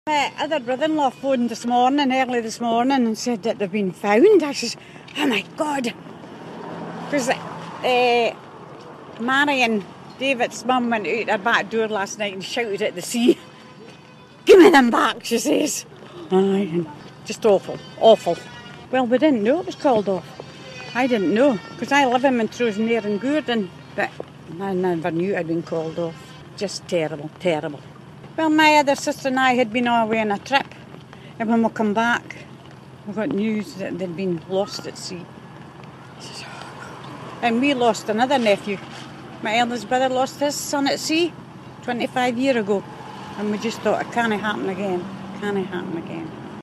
speaks to reporters